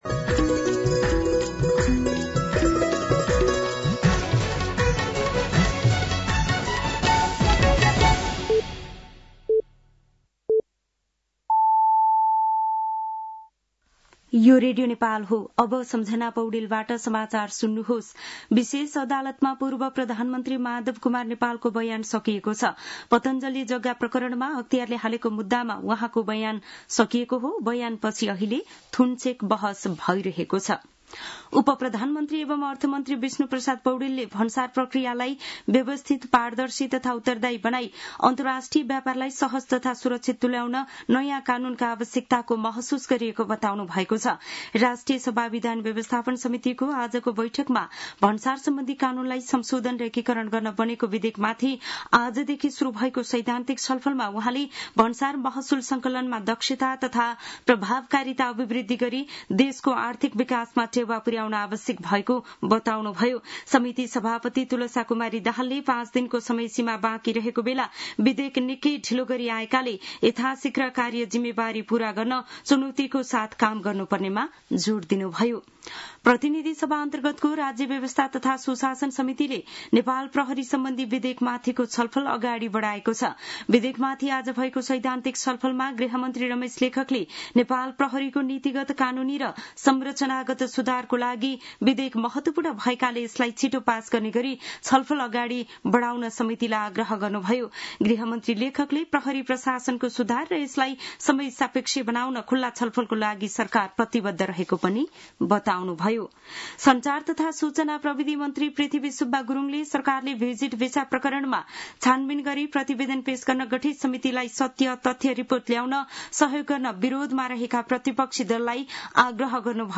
साँझ ५ बजेको नेपाली समाचार : ११ असार , २०८२